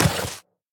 biter-roar-mid-9.ogg